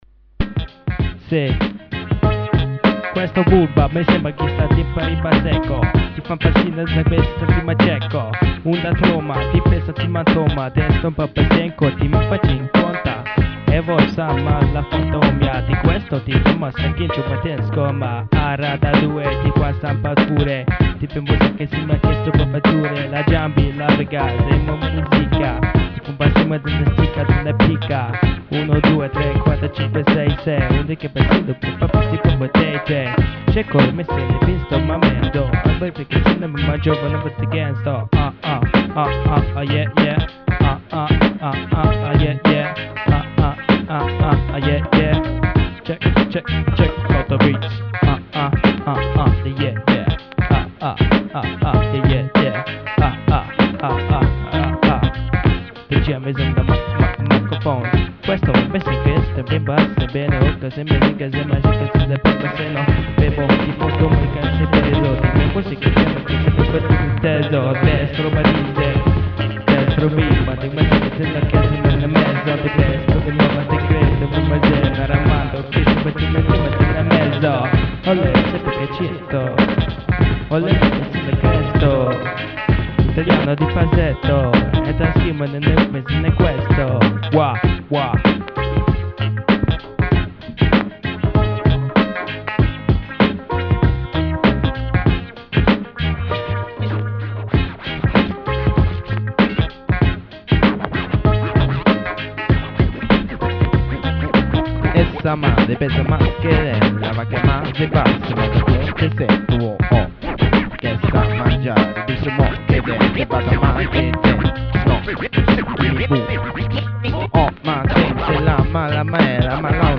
hip-hop session